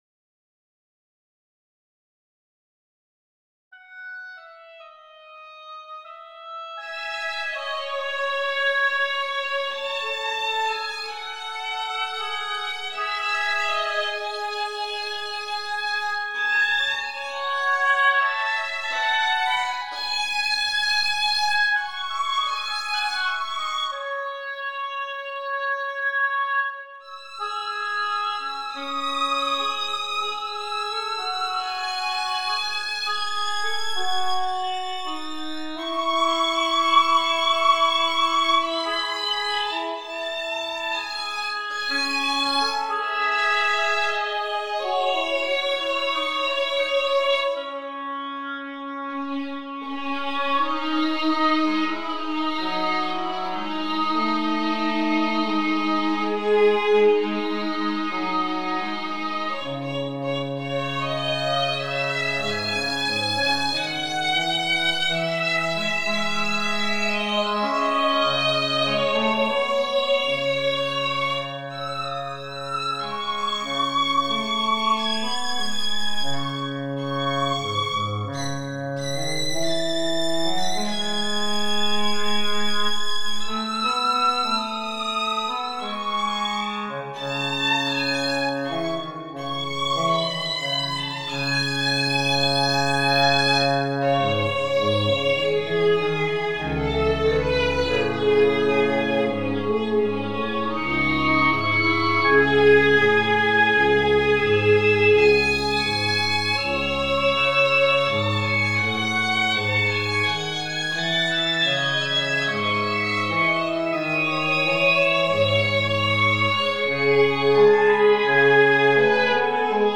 Posted in Classical Comments Off on